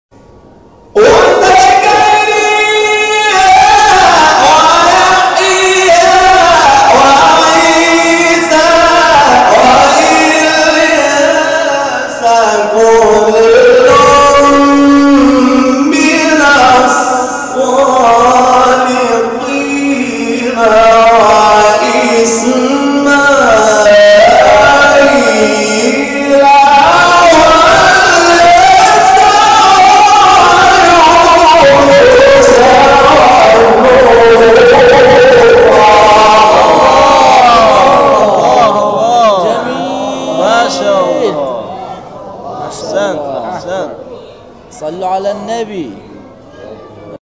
تلاوت سوره انعام